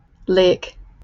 bryt., amer.: IPA/leɪk/